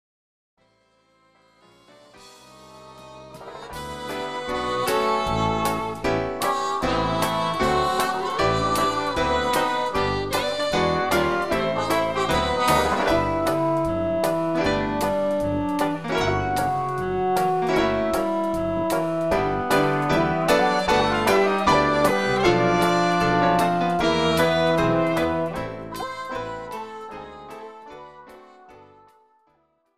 Pop , Rock And Roll